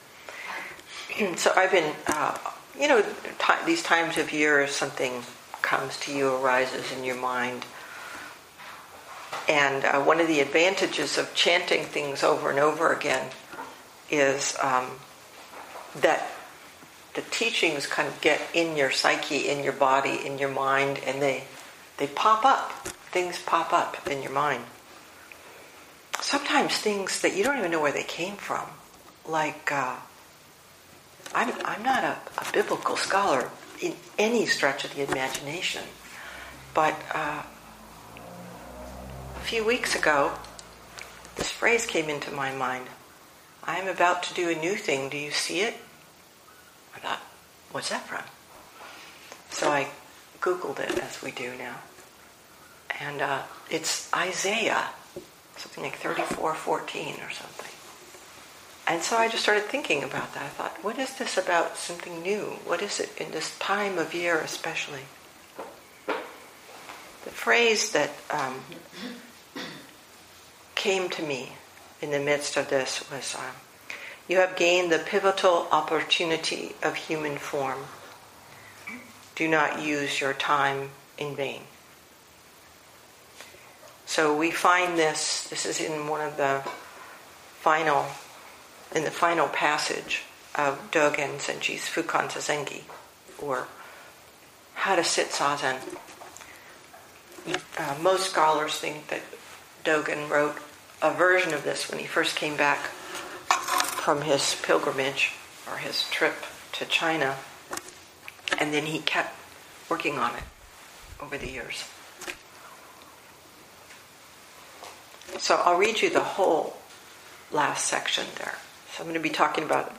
2018 in Dharma Talks